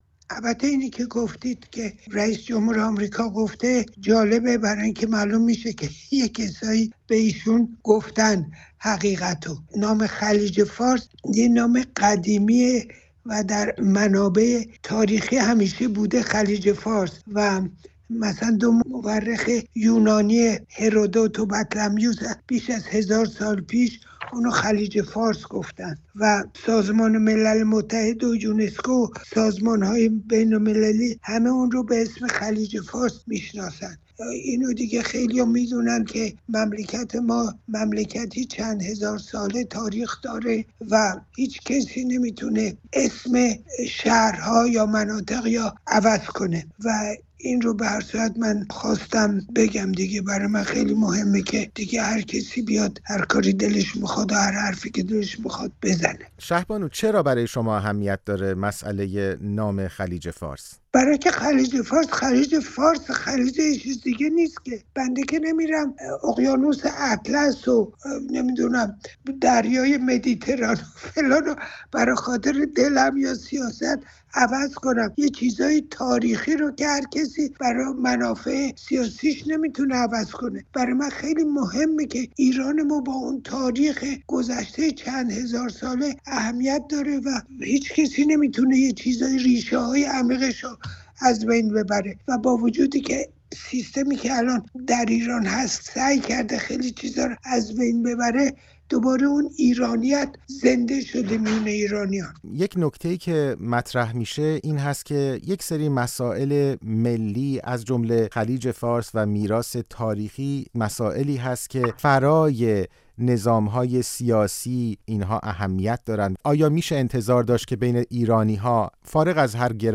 در پی انتشار گزارش‌هایی مبنی بر احتمال تغییر نام خلیج فارس در مدارک رسمی ایالات متحده آمریکا، دونالد ترامپ گفت در این باره هنوز تصمیم نگرفته است. شهبانو فرح پهلوی در گفت‌وگو با رادیوفردا به این خبر واکنش نشان داده است.